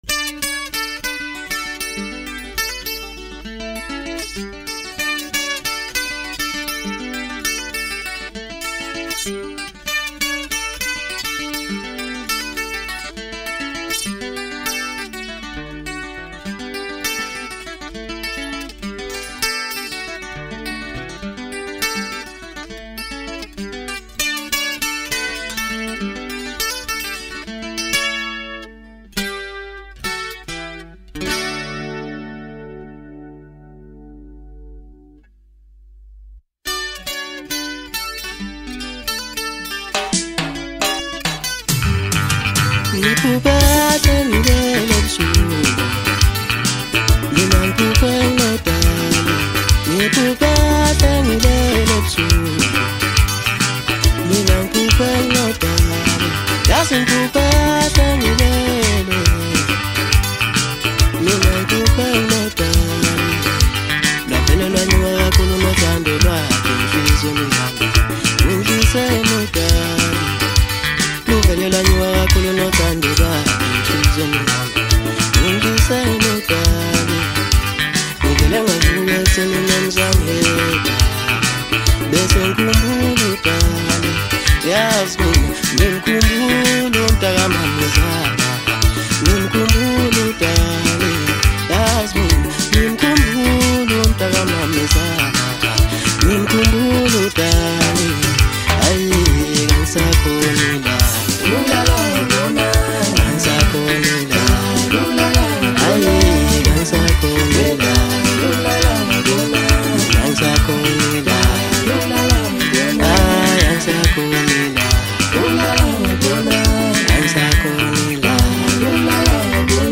Home » Hip Hop » DJ Mix » Maskandi